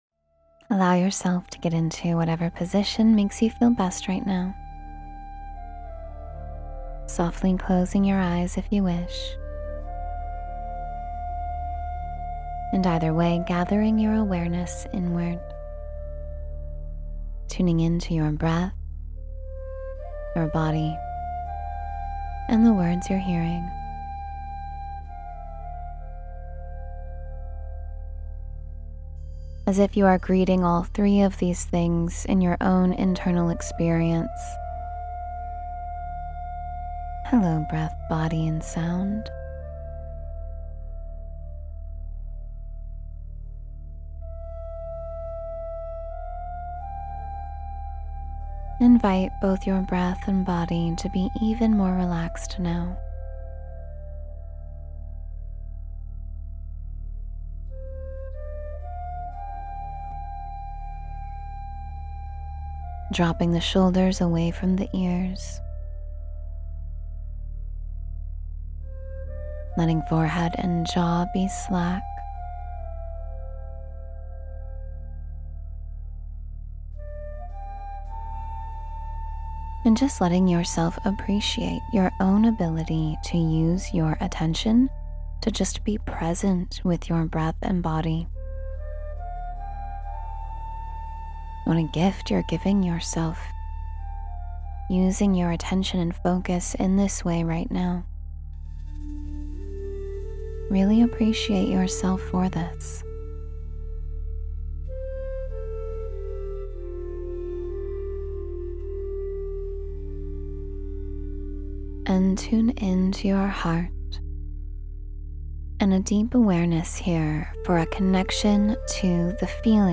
Connecting with the Universe — A Meditation for Oneness